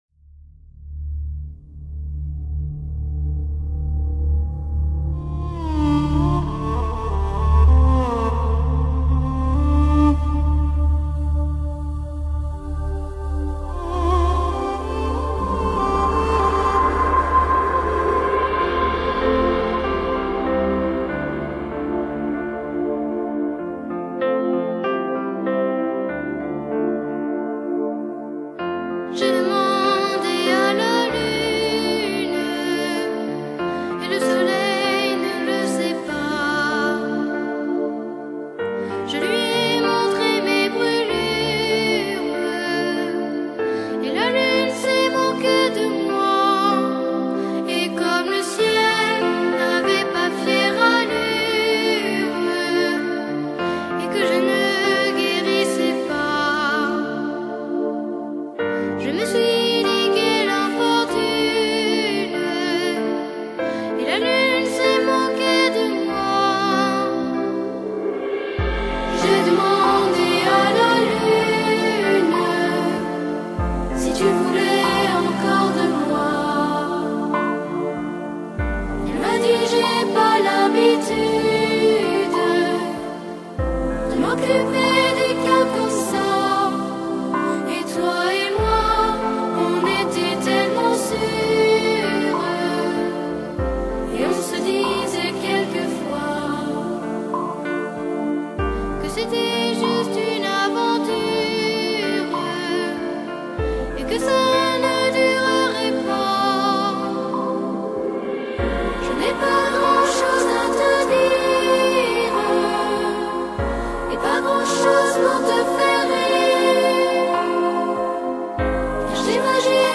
法国新出道的童声合唱组，演唱风格轻柔舒缓，曲目以歌剧、流行歌曲为主。
用清新的童音美声演唱著名歌剧